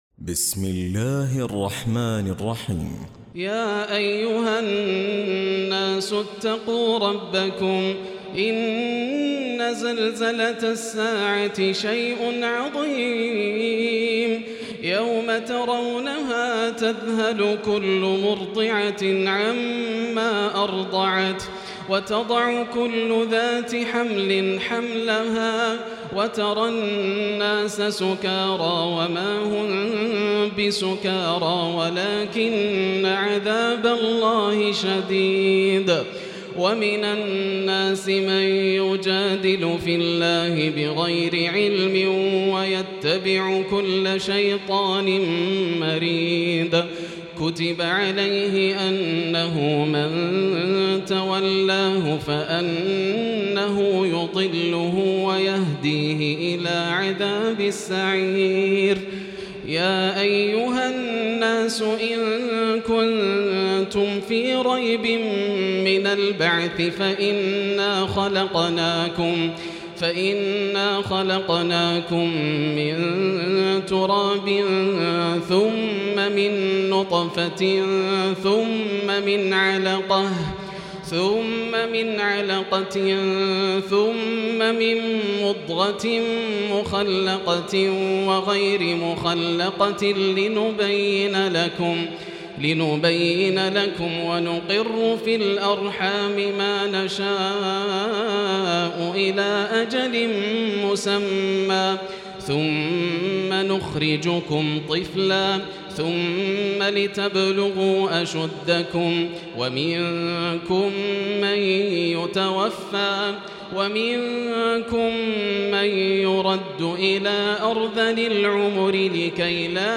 تراويح الليلة السادسة عشر رمضان 1438هـ سورة الحج كاملة Taraweeh 16 st night Ramadan 1438H from Surah Al-Hajj > تراويح الحرم المكي عام 1438 🕋 > التراويح - تلاوات الحرمين